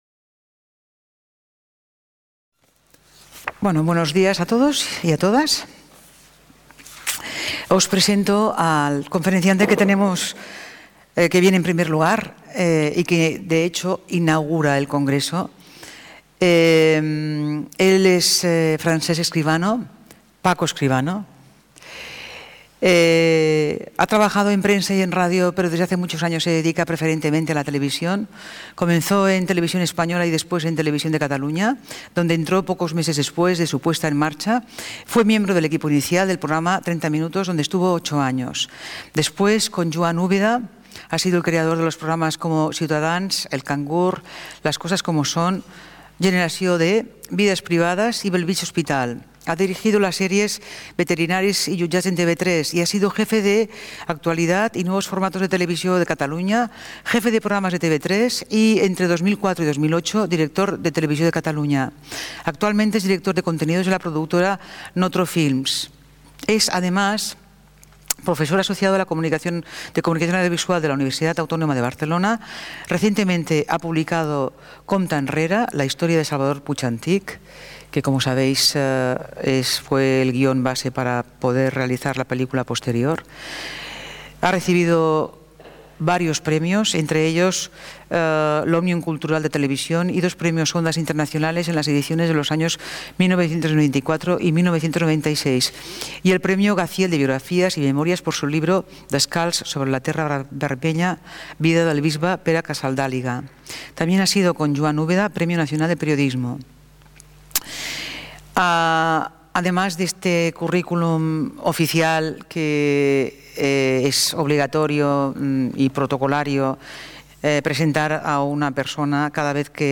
Conferència inaugural